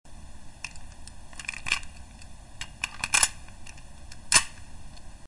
Gun Sound Effects MP3 Download Free - Quick Sounds